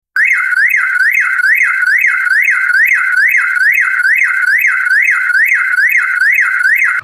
Car Alarm 03
Car_alarm_03.mp3